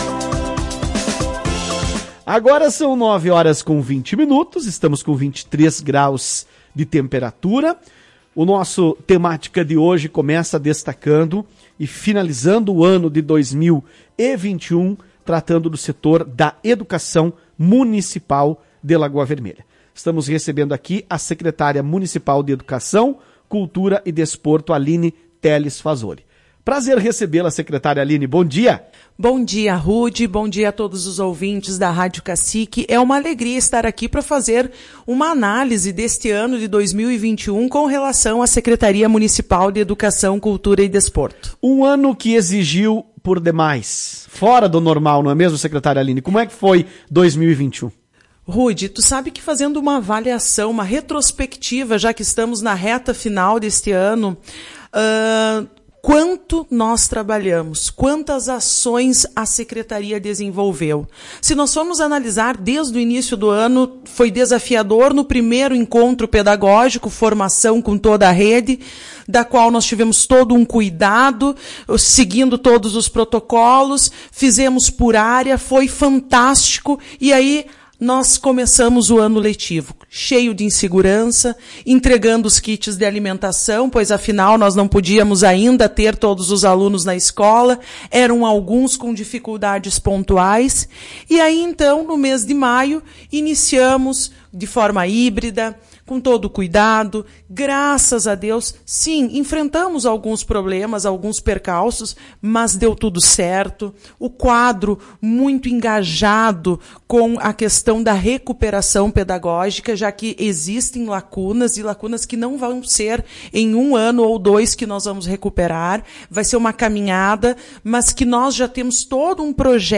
Em entrevista à Tua Rádio Cacique, a secretária de Educação de Lagoa Vermelha, Aline Teles Fasoli, relembrou o trabalho da pasta e os desafios encontrados ao longo do caminho.